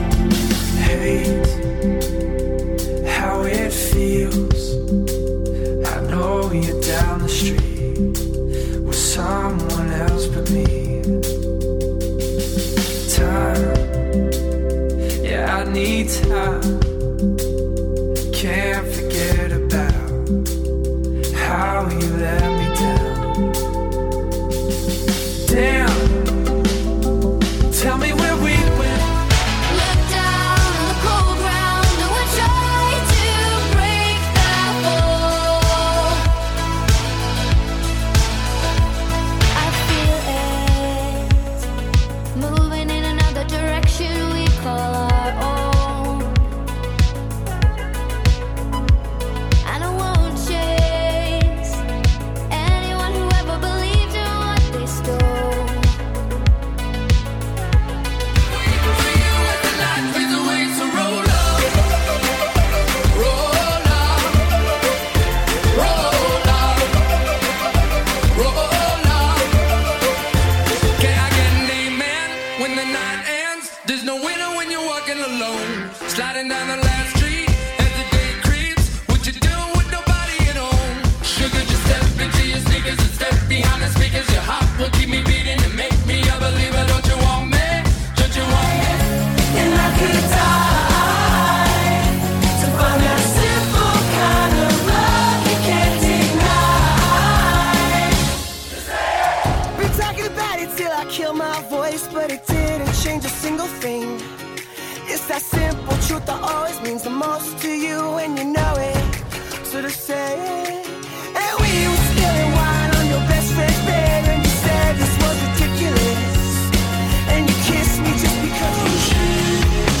Afternoon Indie
Best of Indie and Nu Disco Music